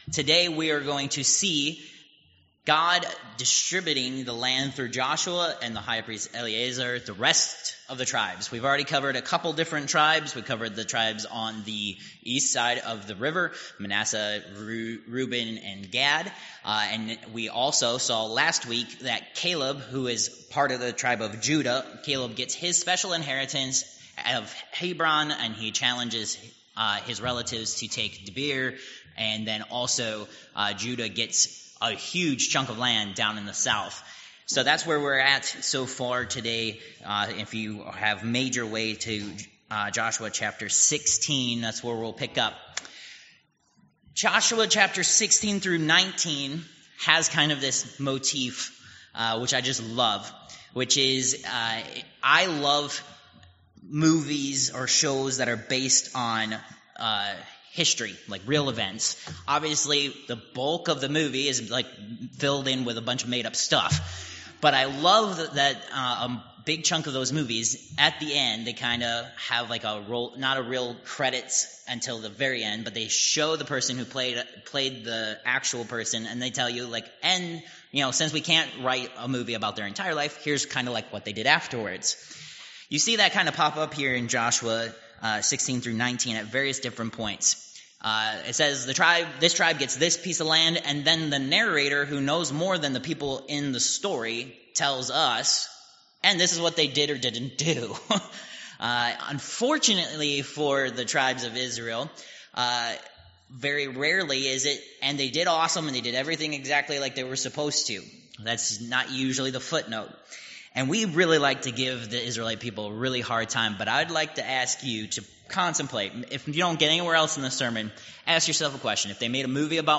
Josh. 16-19 Service Type: Worship Service Download Files Notes « Protected